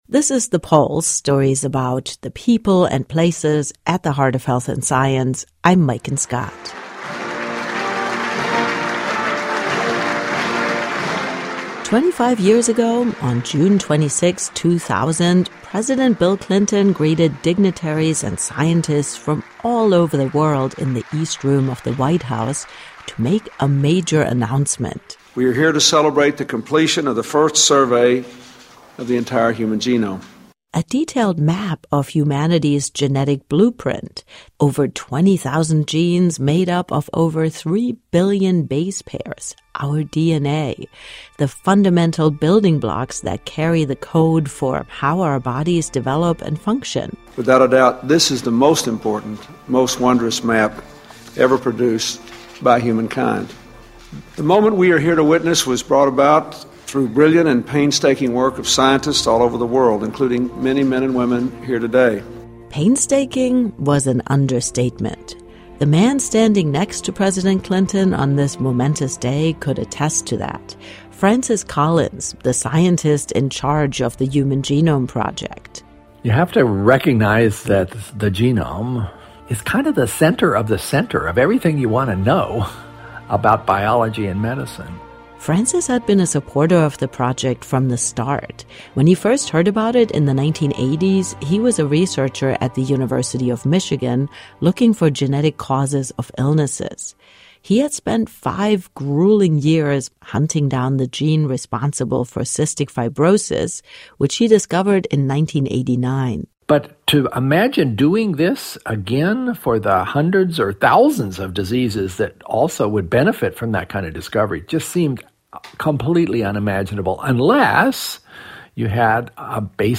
Lots of people had fun with an app that allows you to dramatically age yourself in a picture. In their weekly conversation